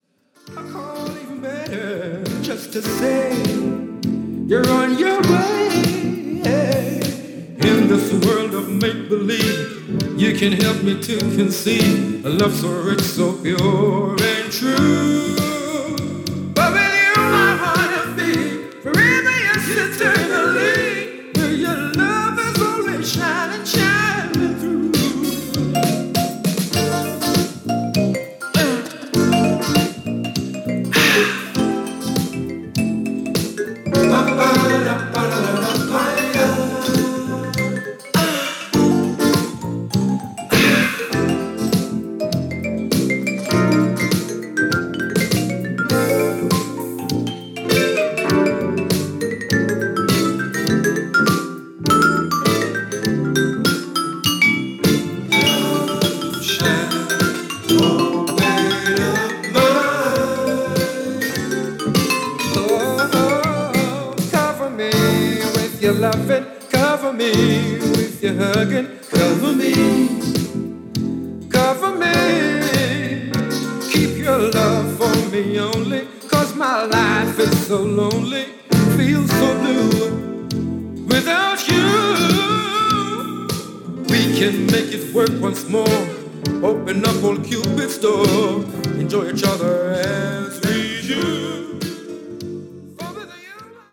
UKらしい洗練されたサウンドのイメージですね！！！